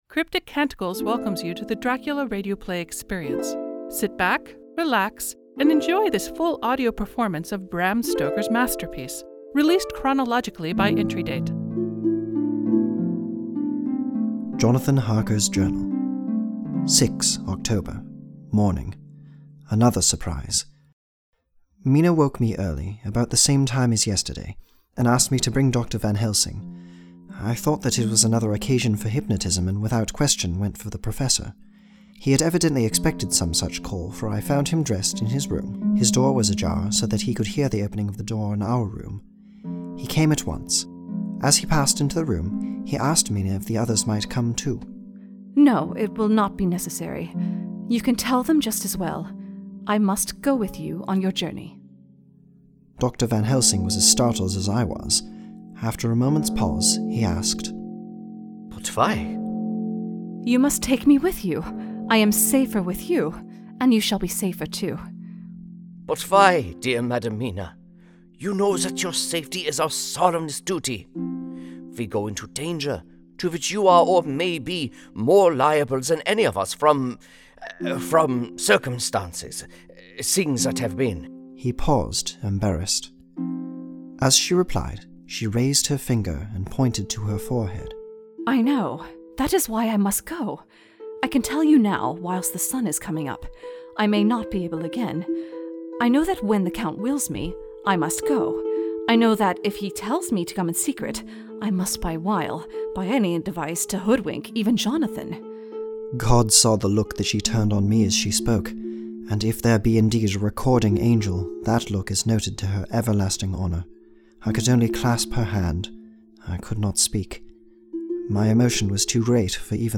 This is a rebroadcast of the original 2017 work.